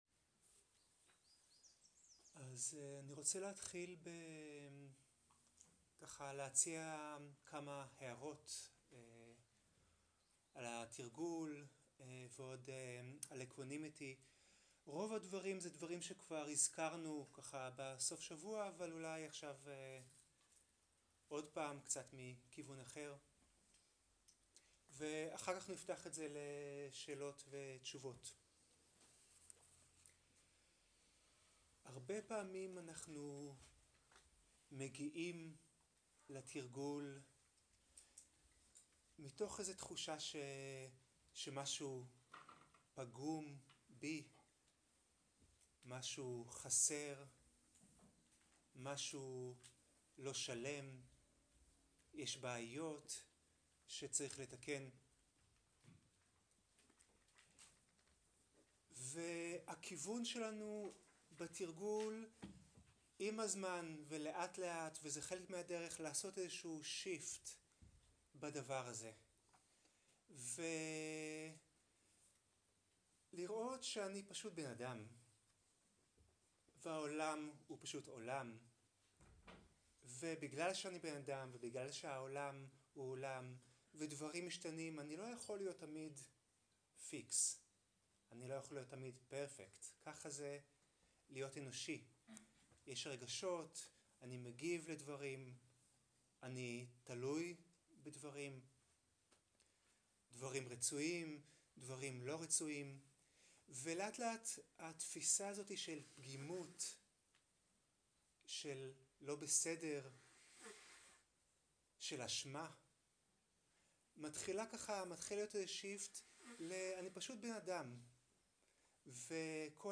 שאלות ותשובות
איכות ההקלטה: איכות גבוהה